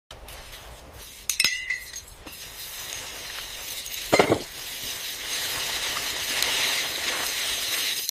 ASMR glass garden vegetables, cucumber